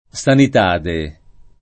sanità [Sanit#+] s. f. — ant. sanitade [